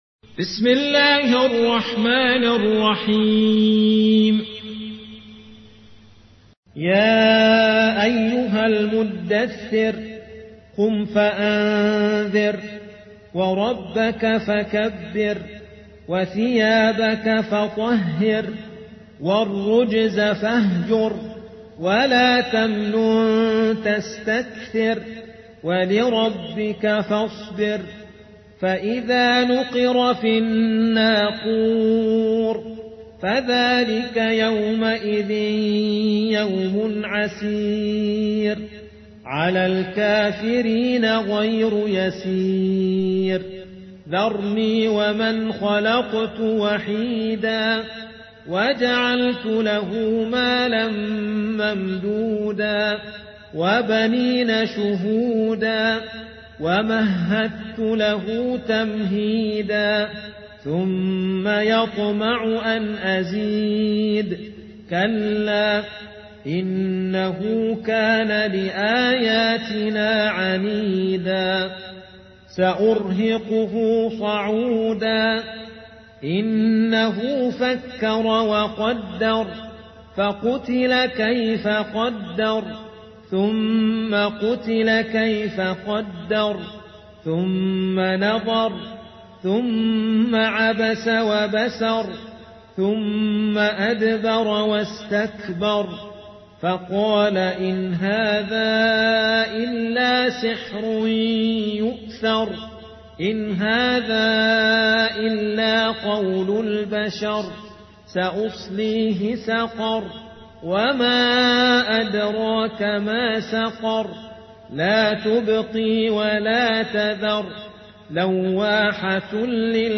74. سورة المدثر / القارئ